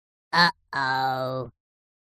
Звуки ооу
На этой странице вы найдете разнообразные звуки «ооу» — от удивления и легкой досады до комичного возгласа.
Ооу попался